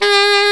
Index of /90_sSampleCDs/Roland L-CD702/VOL-2/SAX_Alto Short/SAX_Pop Alto
SAX F#3 S.wav